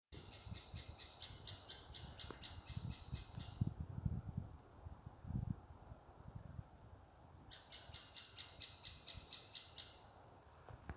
Middle Spotted Woodpecker, Leiopicus medius
StatusPair observed in suitable nesting habitat in breeding season